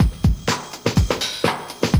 JUNGLEBRE00L.wav